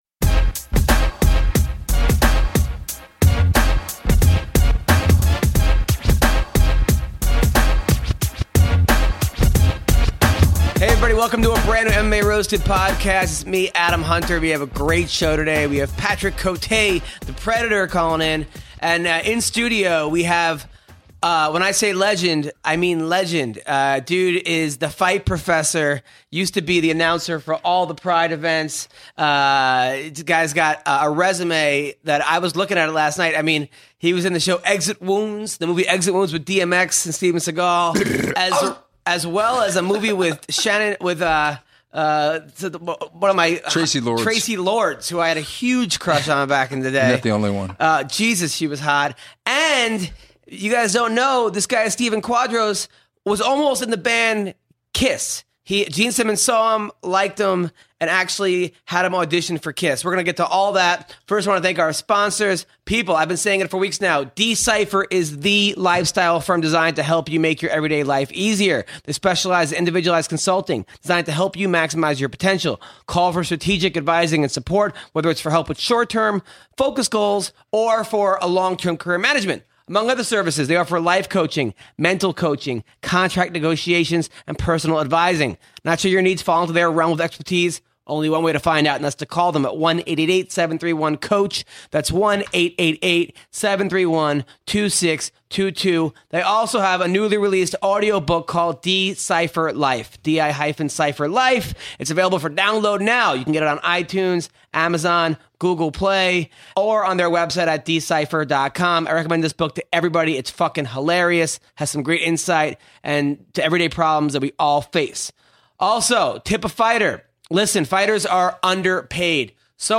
Also, the crew takes a call from UFC welterweight Patrick Cote.